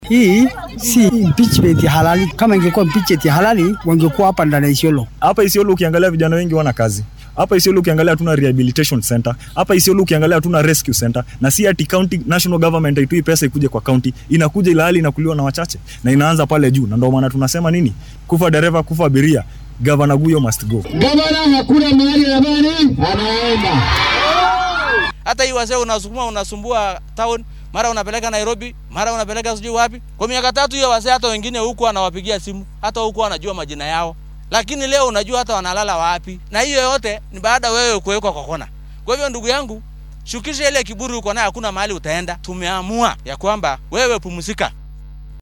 DHEGEYSO:Shacabka Isiolo oo ka hadlay qorshaha xilka looga qaadaya barasaabka ismaamulkaasi
Waxaa uu ku eedeynaya mashaariic badan oo uu hakad galay dhaqangelintooda iyo in maamulkiisa uunan ku guulaysan uruurinta dakhliga canshuureed ee loo baahnaa. Qaar ka mid ah dadweynaha ku nool Isiolo ayaa siyaabo kala duwan uga hadlay qorshaha xilka looga xayuubinaya Cabdi Guyo.